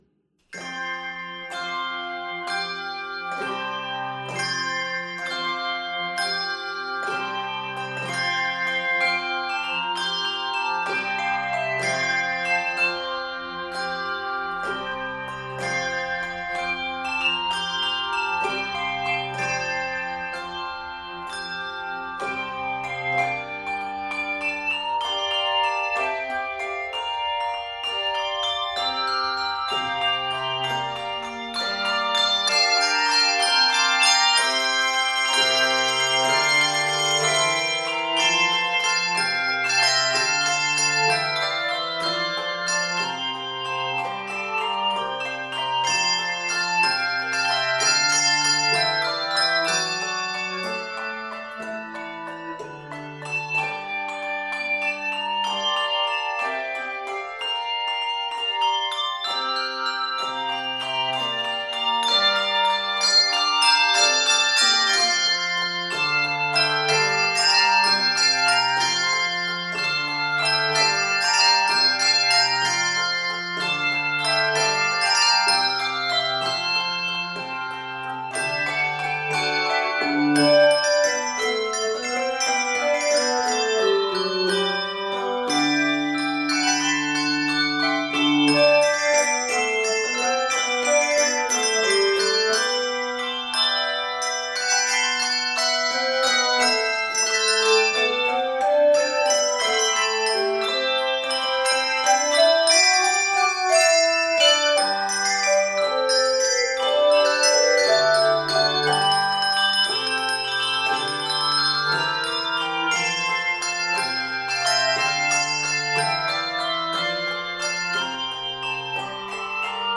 upbeat and bouncy original work
N/A Octaves: 3-5 Level